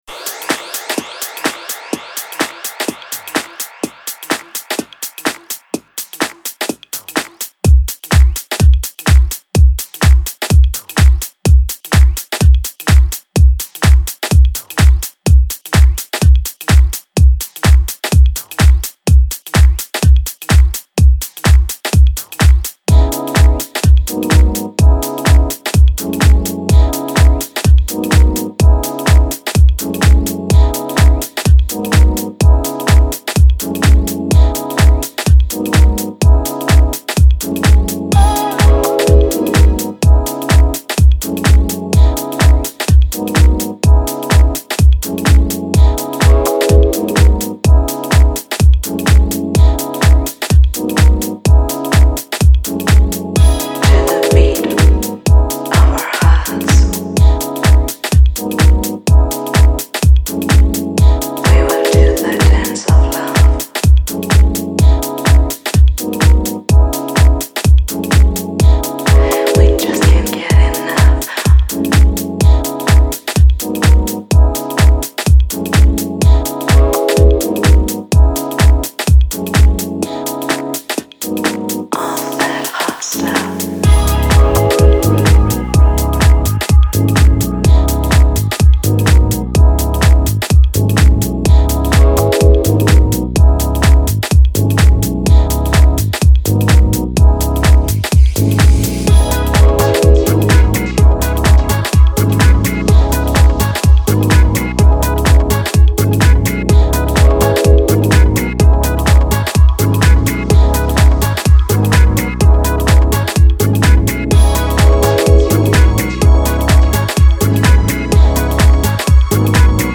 Genre: Deep House, Chillout, Downtempo.